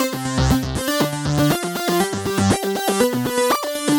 Index of /musicradar/french-house-chillout-samples/120bpm/Instruments
FHC_Arp C_120-C.wav